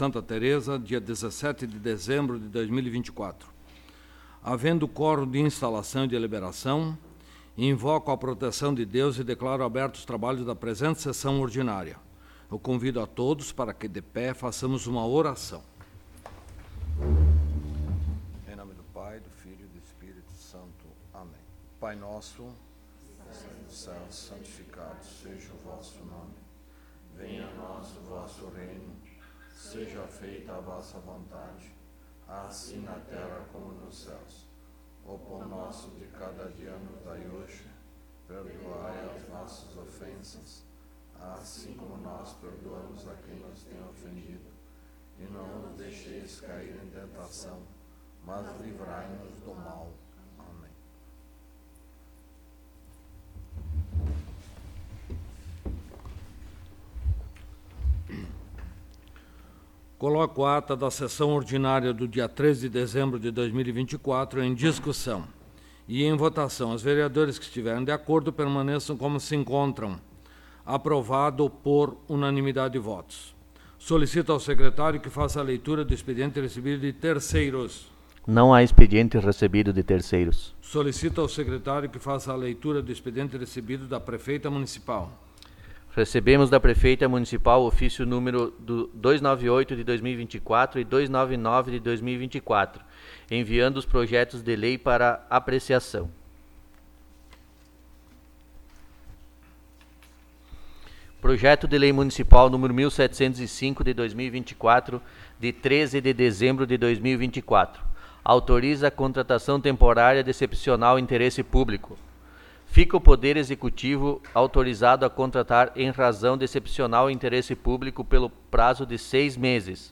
22° Sessão Ordinária de 2024
Local: Câmara Municipal de Vereadores de Santa Tereza